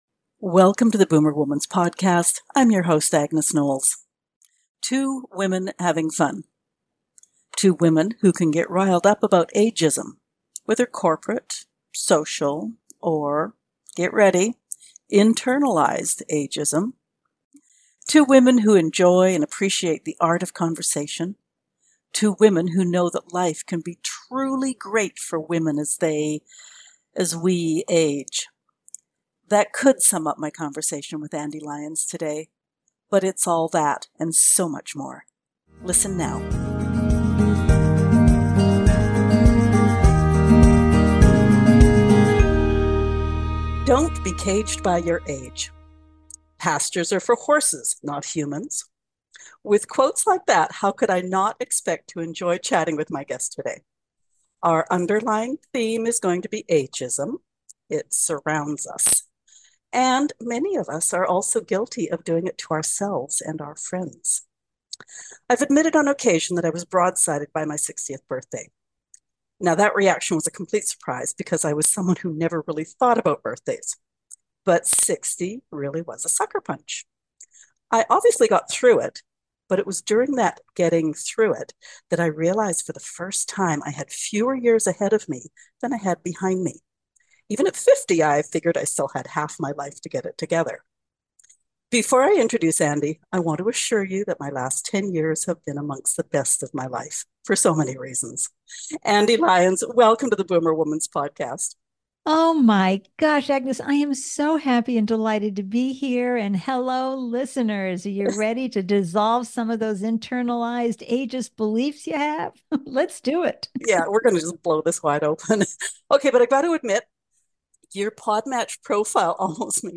So much laughter.